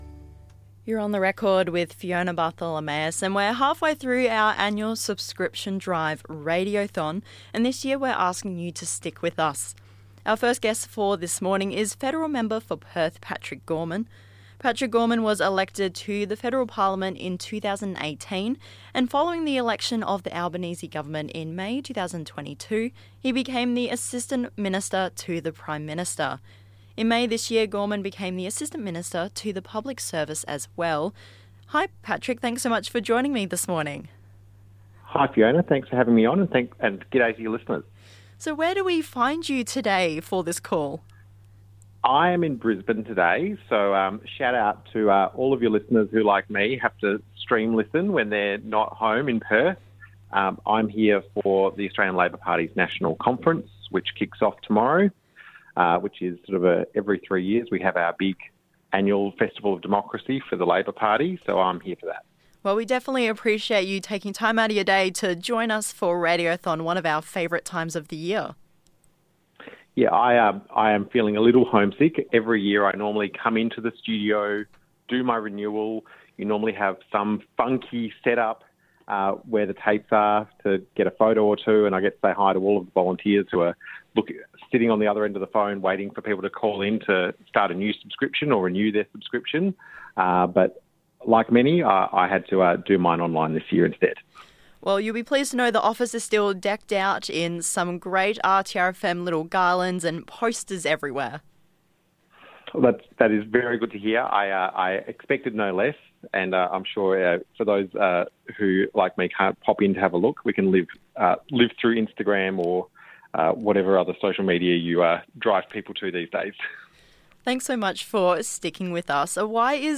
We’re halfway through our annual subscription drive Radiothon, and today we’re joined by a guest who is no stranger to radio and especially RTRFM.
Our first guest for this morning is the Federal Member for Perth, Patrick Gorman.
Mr. Gorman chats with us about the importance of community radio, and how the Federal Government is working to support community broadcasters across Australia through legislation and funding.